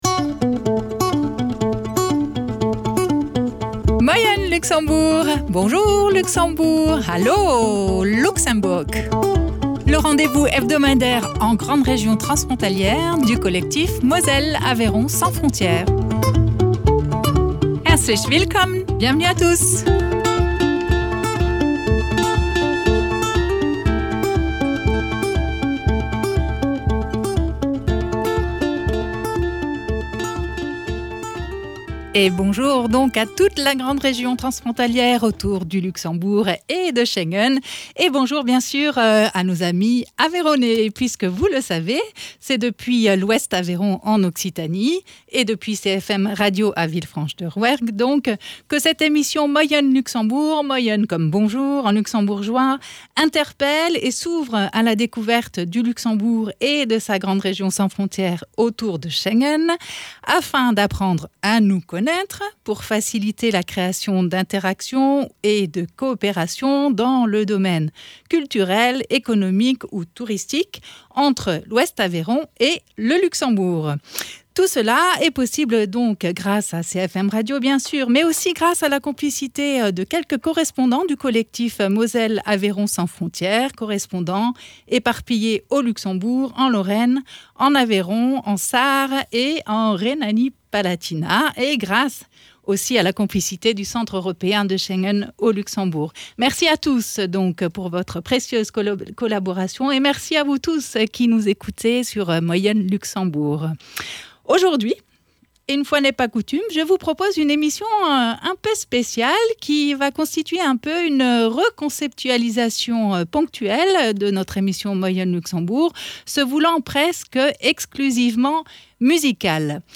Petit florilège de séquences en musique ou en chanson d’artistes originaires de nos territoires de découvertes et qui racontent quelque chose de l’Aveyron, de l’Occitanie ou de la Grande Région (Luxembourg, Lorraine, régions allemandes de Sarre, Rhénanie Palatinat, voire de Rhénanie-du Nord Wesphalie).